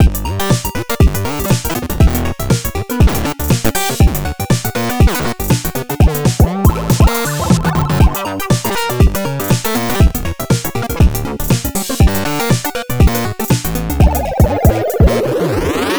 022_HOT DOG BASS SAMP1.wav